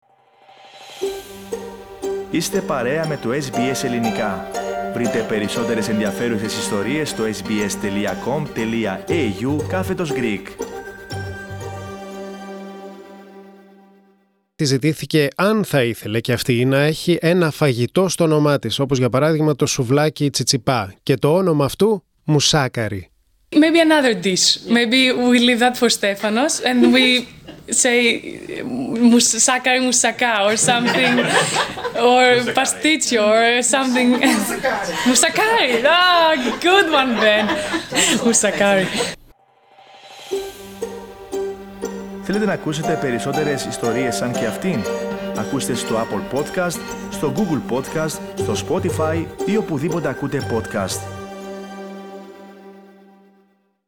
Δεν είναι fake news, το δήλωσε η Ελληνίδα πρωταθλήτρια Μαρία Σάκκαρη σε συνέντευξη Τύπου στη Μελβούρνη, μετά την νίκη της στον πρώτο γύρο του Australian Open!